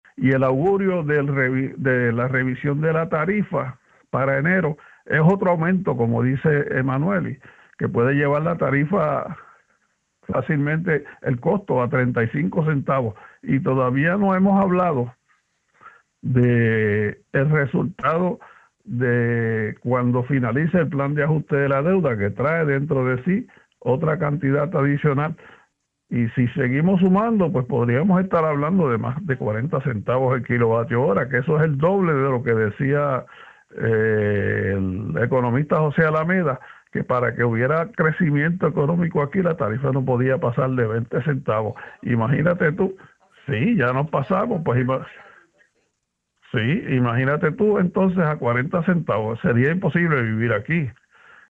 en entrevista con Radio Isla.